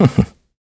Riheet-Vox-Laugh.wav